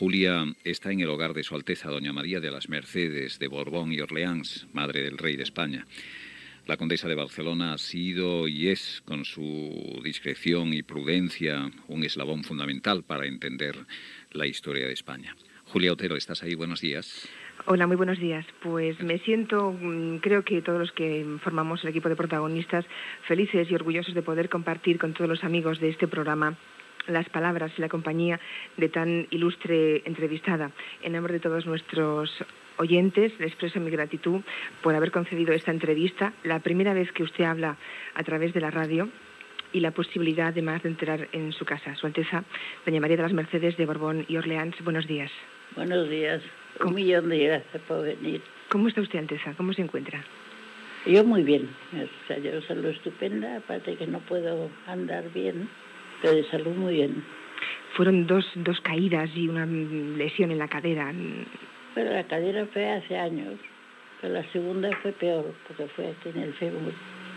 Fragment d'una entrevista de Julia Otero a la "condesa de Barcelona", María de las Mercedes Borbón y Orleans, feta a casa seva